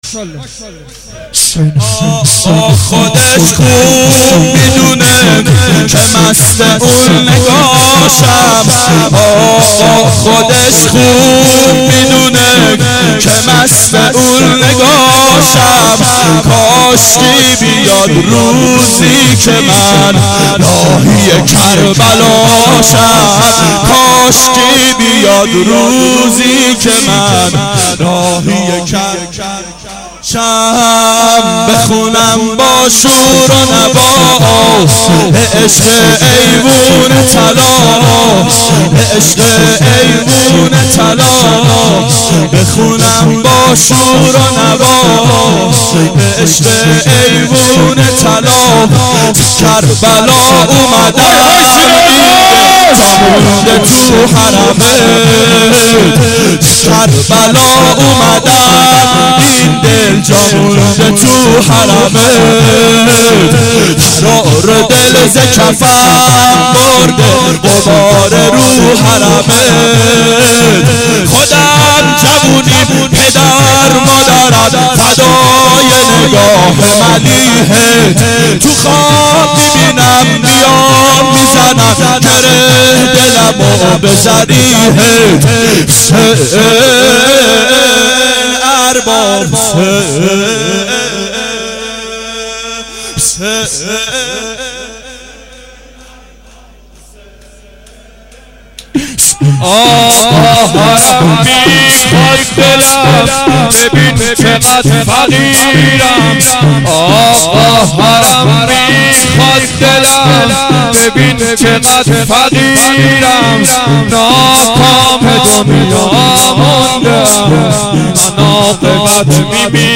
اربعین 90 هیئت متوسلین به امیرالمؤمنین حضرت علی علیه السلام